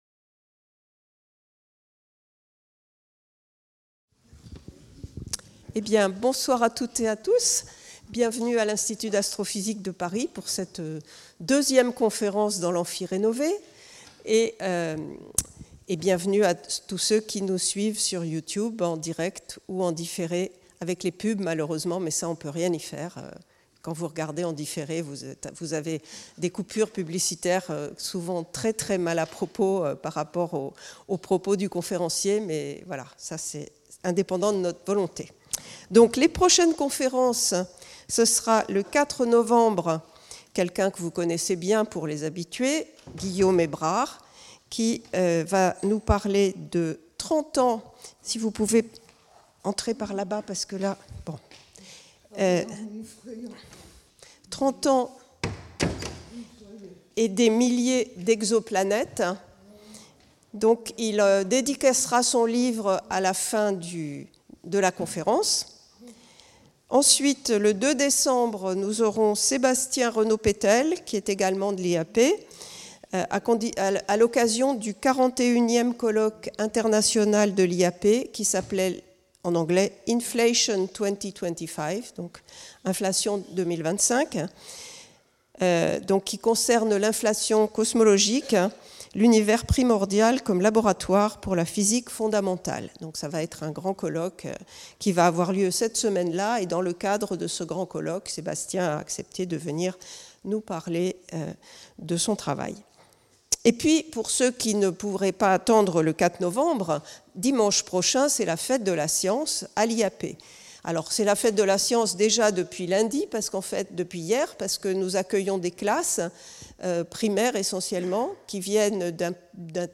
Cette conférence du cycle mensuel des conférences publiques de l'IAP s'est tenue mardi 7 octobre 2025 à 19h30, et a été donnée par